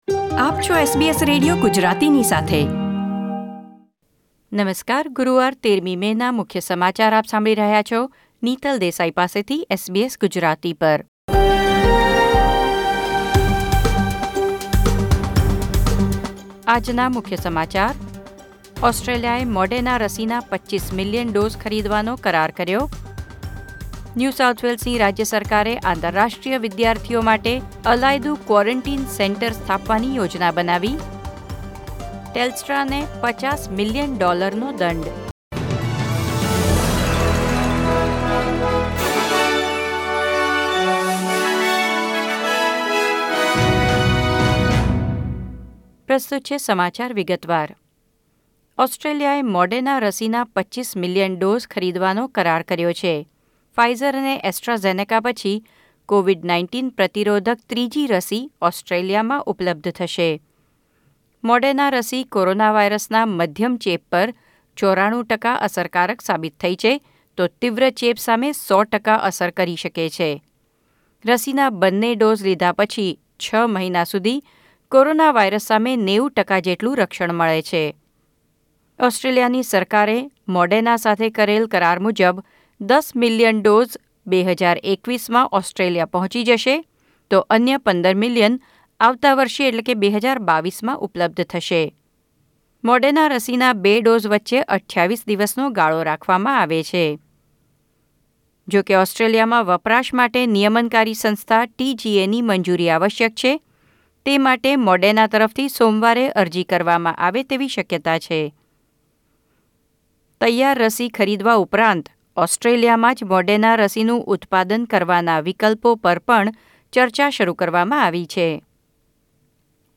SBS Gujarati News Bulletin 13 May 2021